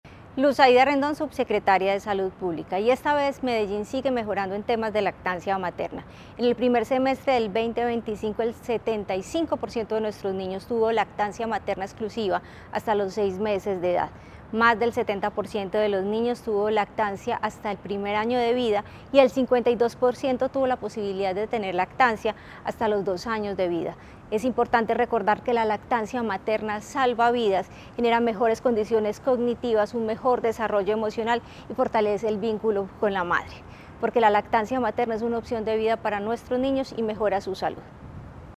Declaraciones de la subsecretaria de Salud Pública, Luz Aida Rendón
Declaraciones-de-la-subsecretaria-de-Salud-Publica-Luz-Aida-Rendon.mp3